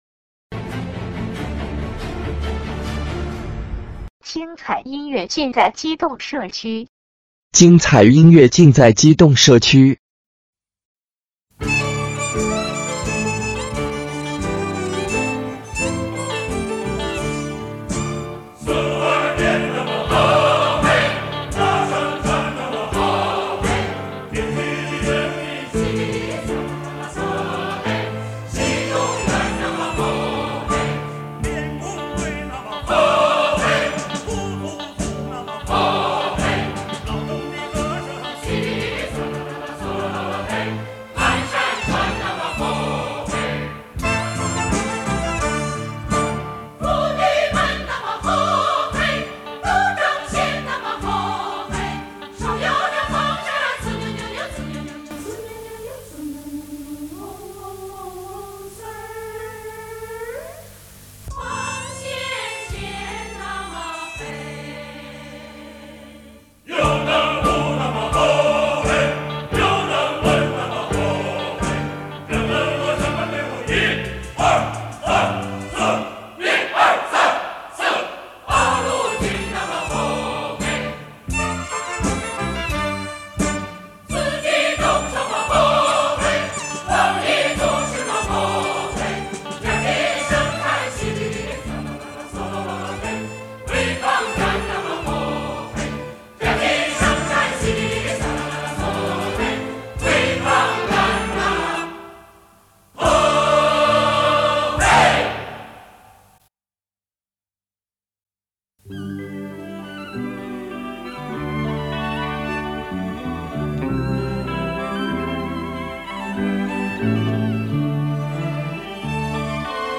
陇东民歌
陕北民歌